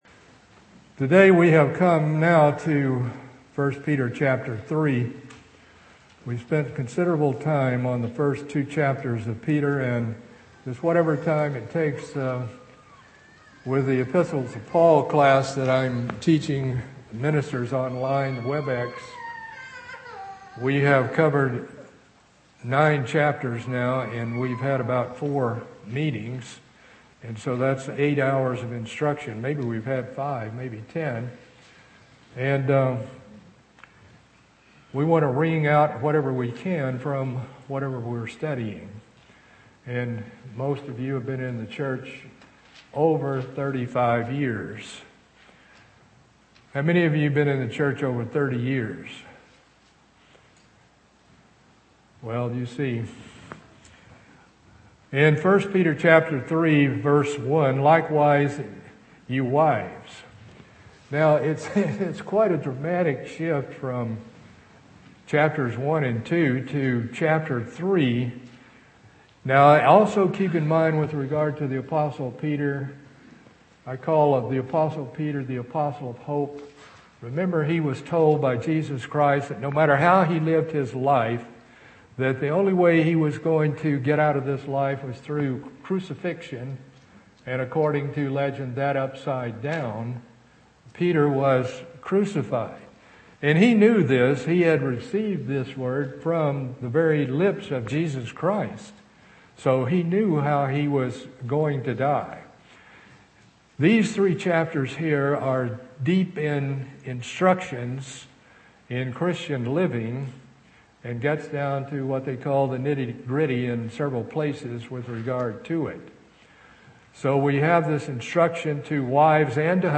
Continuing Bible study covering 1 Peter, chapters 3-4.